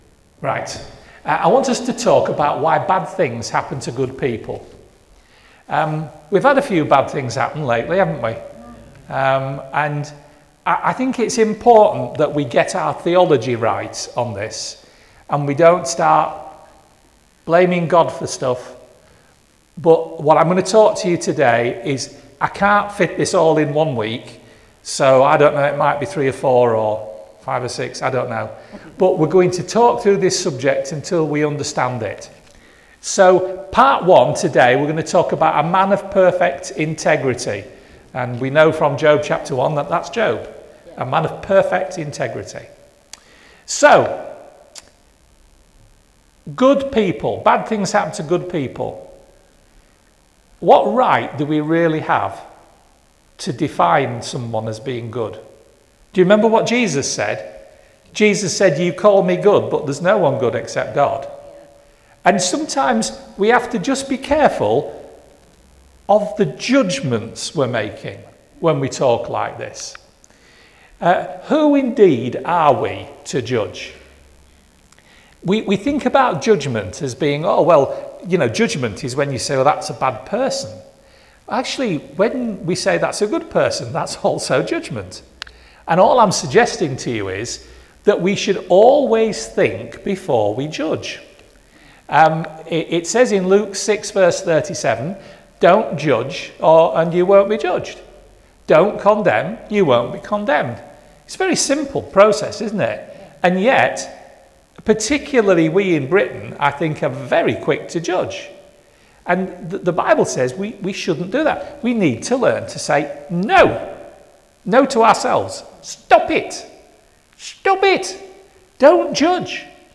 preaching series